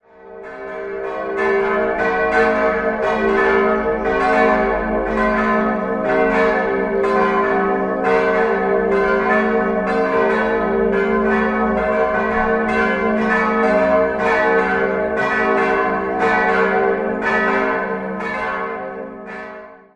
4-stimmiges Geläut: g'-a'-c''-d'' Die kleine Glocke stammt aus dem Jahr 1931 und wurde sehr wahrscheinlich von Hamm in Regensburg gegossen. Alle übrigen sind Werke derselben Gießerei aus dem Jahr 1948.